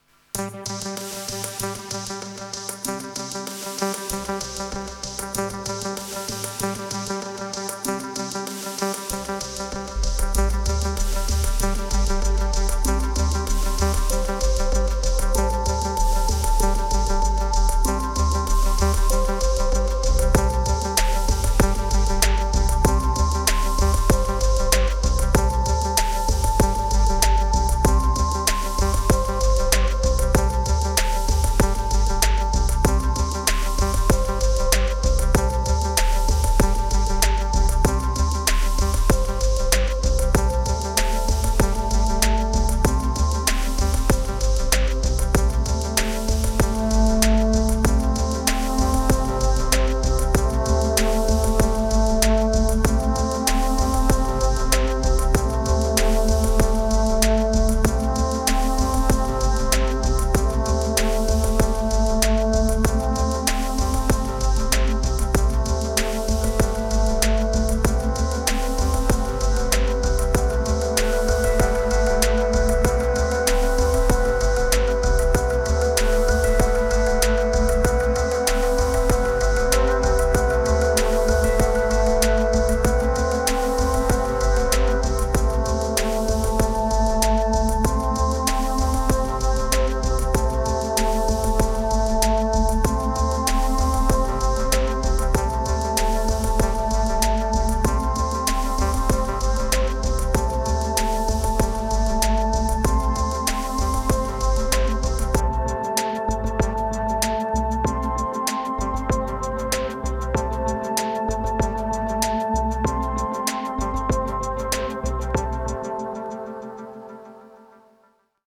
606📈 - 82%🤔 - 96BPM🔊 - 2025-03-29📅 - 437🌟
Quick andquite dirty ambient beat.
Beats Sampler Lateral Omni Abstract Drums Bass Relief